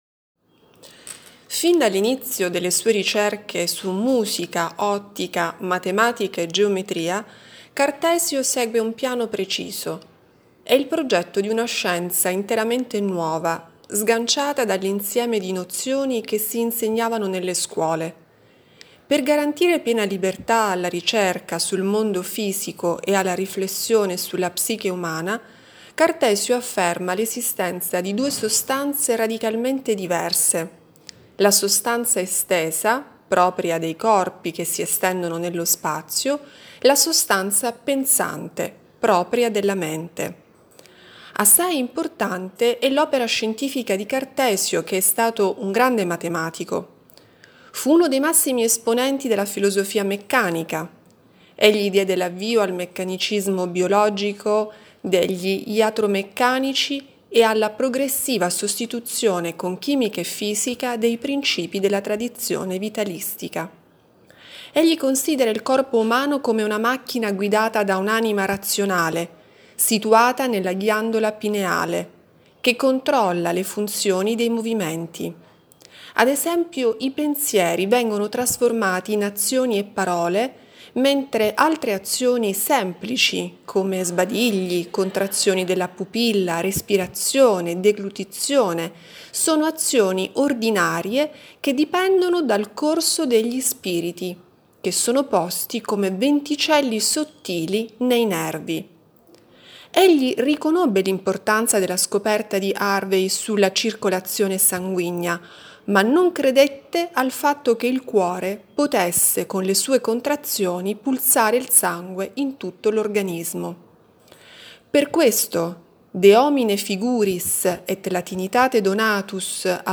Audioguida Volumi Esposti nel 2022